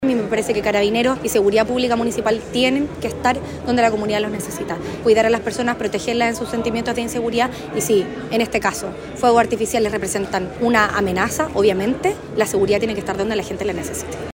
La alcaldesa de Viña del Mar, Macarena Ripamonti, apuntó a la “amenaza” que constituye este tipo de situaciones.